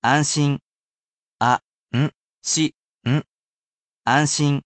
I personally sound out each word or phrase aloud for you to repeat as many times as you wish, and you can ask me to say it as many times as you wish.